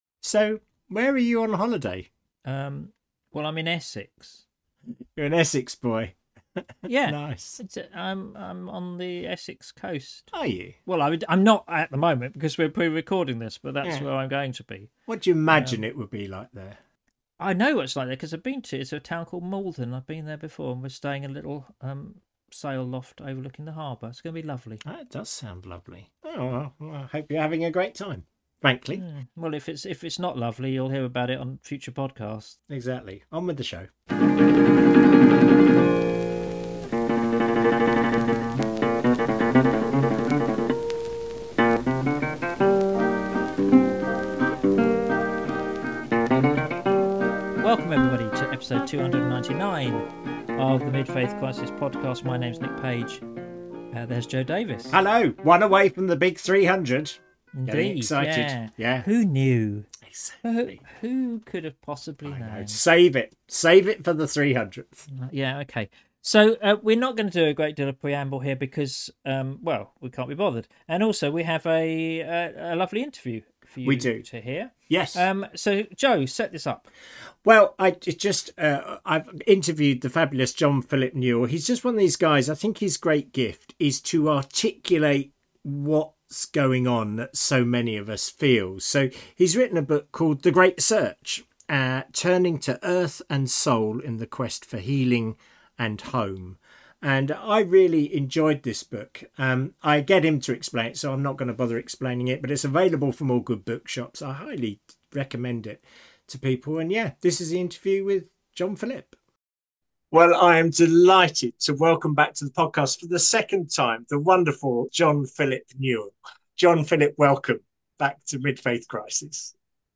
Episode 299: The Great Search - an interview